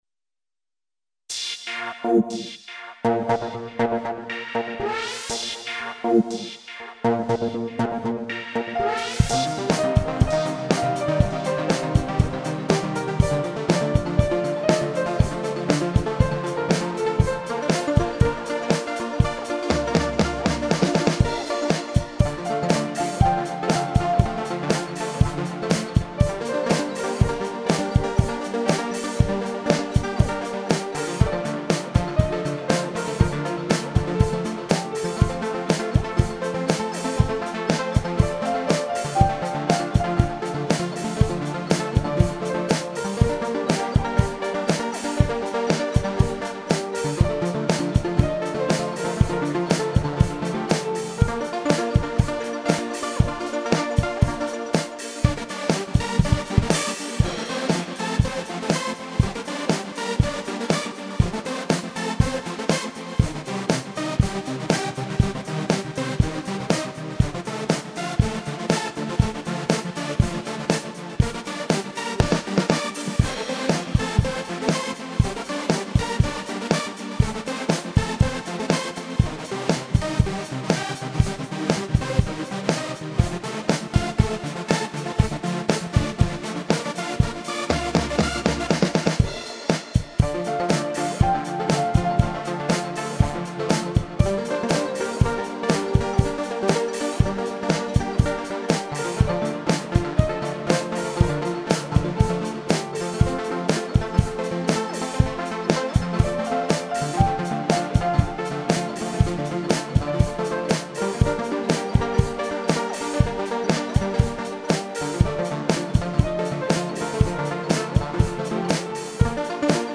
Фонограмма: